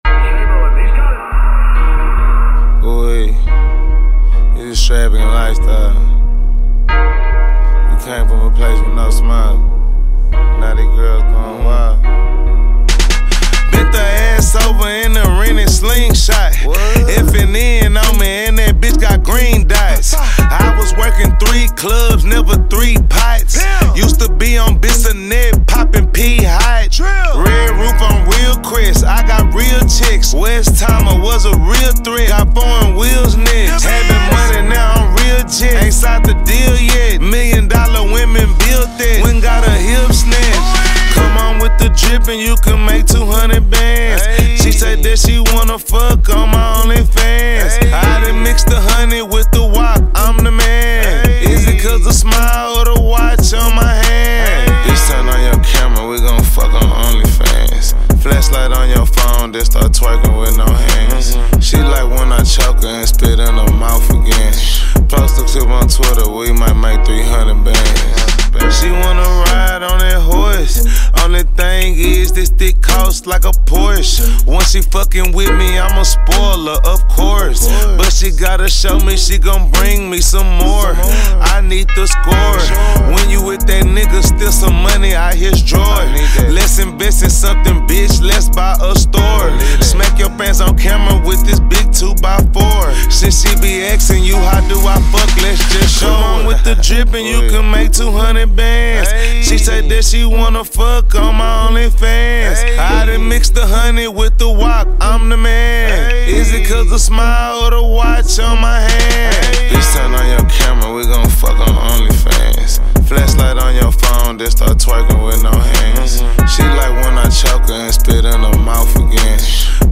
Impressive American rapper and singer